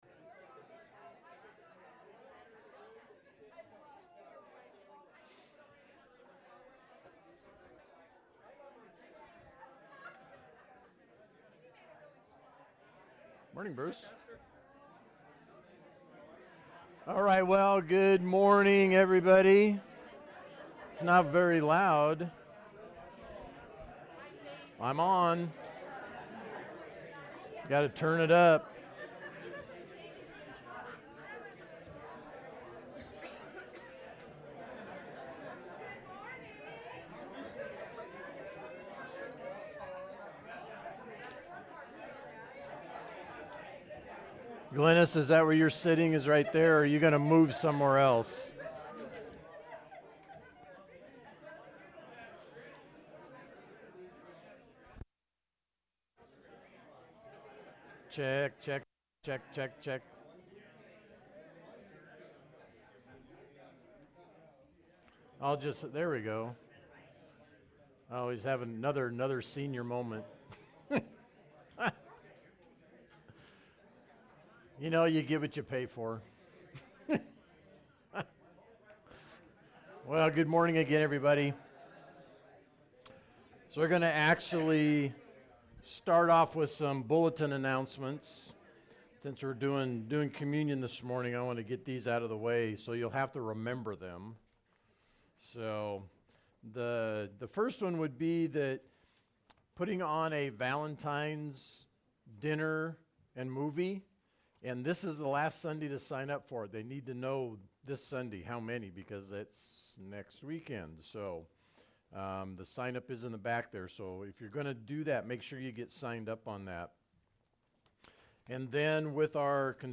Feb5-sermon.mp3